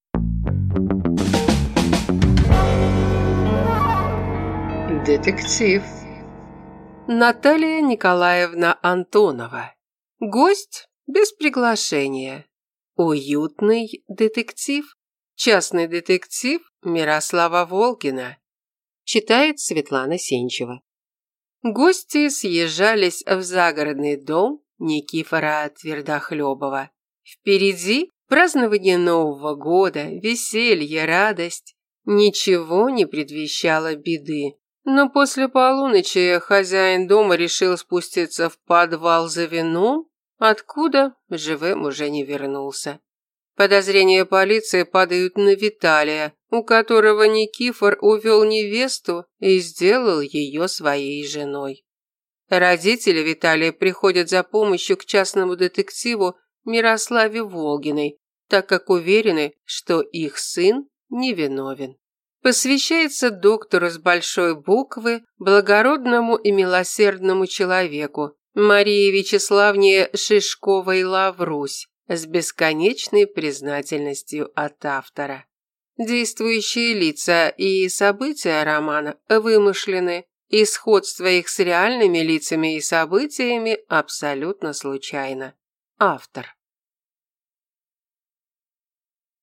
Аудиокнига Гость без приглашения | Библиотека аудиокниг